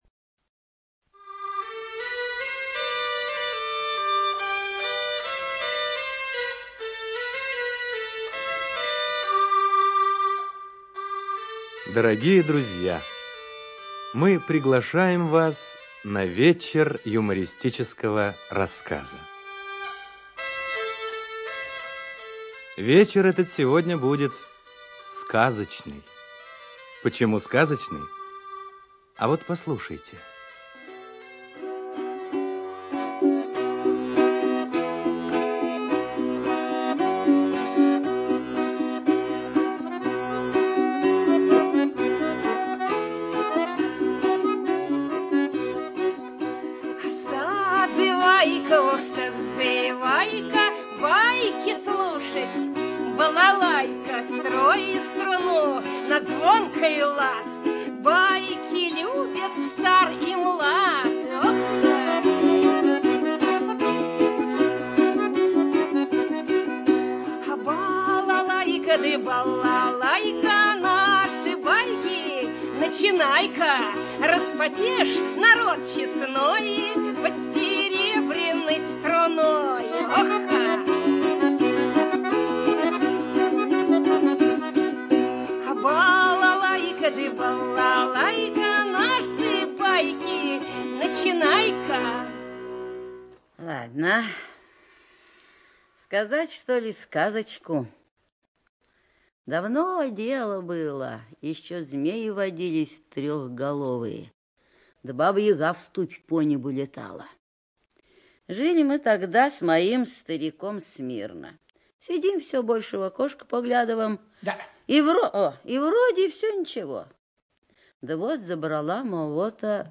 ЧитаютТабаков О., Весник Е., Сазонова Н., Невинный В., Павлов В.